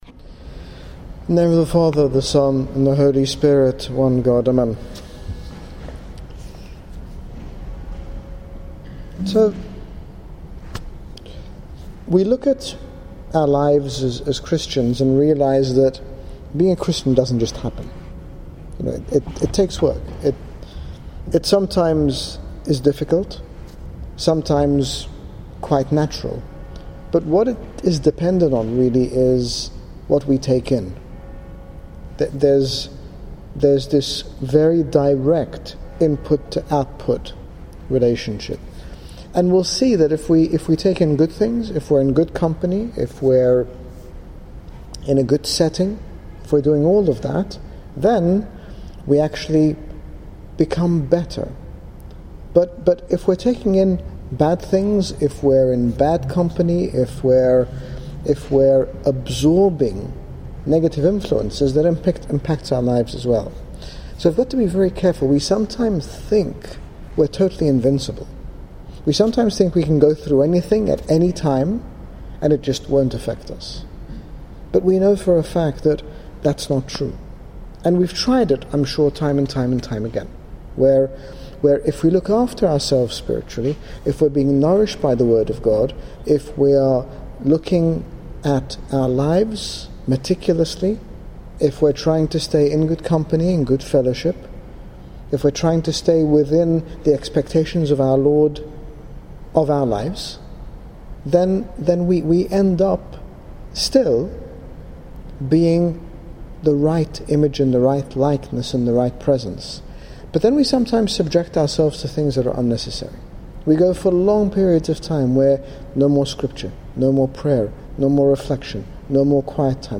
In this talk, His Grace Bishop Angaelos, General Bishop of the Coptic Orthodox Church in the United Kingdom, speaks about the importance of a daily walk with our Lord, ensuring our spiritual journey is not hindered by inconsistency or an accumulation of things that oppose God's will in our lives. Download Audio Read more about Walk with Him daily - HG Bishop Angaelos - Grapevine Fellowship Meeting